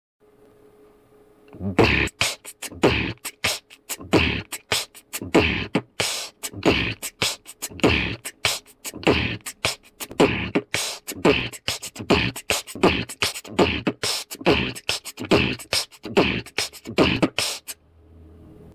4) bm--t-kch-t-t (3 раза)
bm--b-kch--t